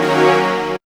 3705L FLUFFY.wav